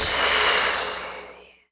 SFX monster_dead_common.wav